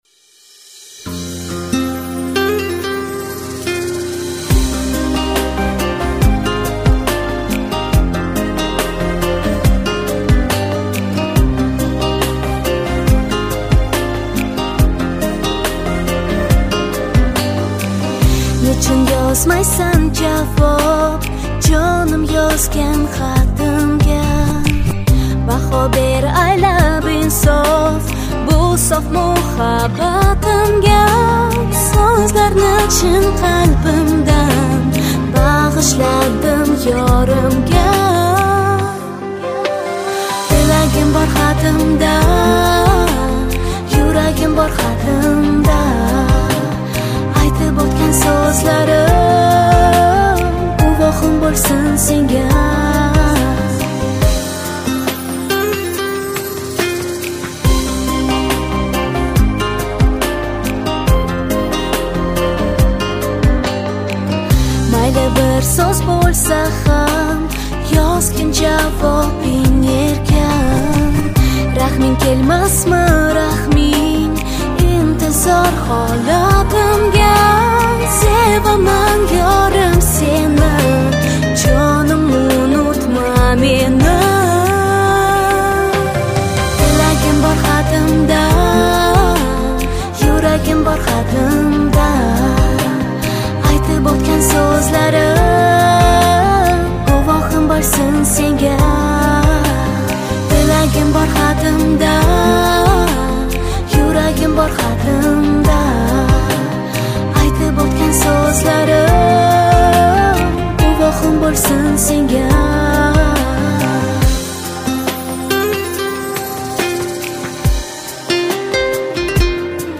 Кавказская музыка
Узбекская песня